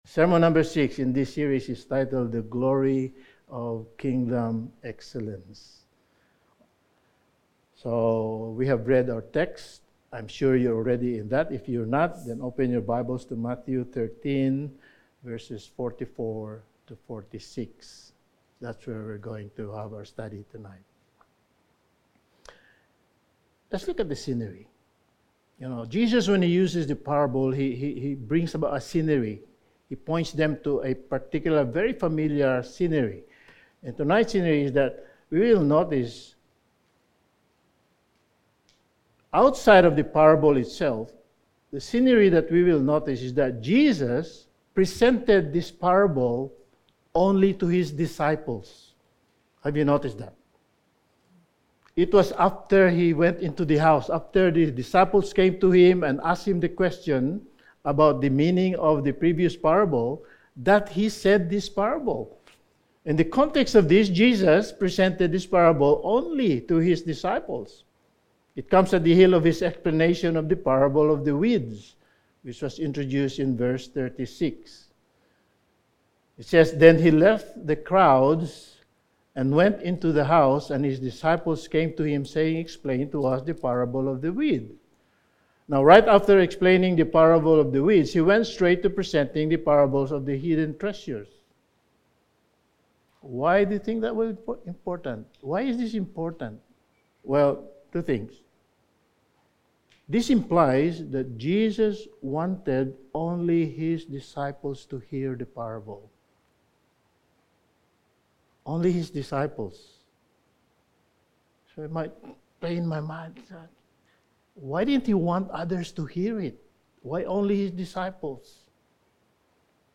Sermon
Service Type: Sunday Evening